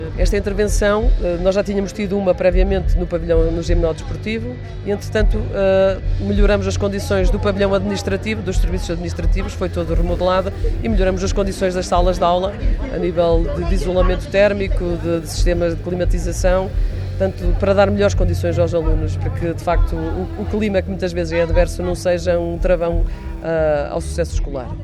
Depois de ter sido intervencionado o pavilhão gimnodesportivo, agora foi a vez do edifício principal, numa estratégia de melhoramento das infraestruturas educativas do concelho, como contou Maria Manuel Silva, vice-presidente da autarquia: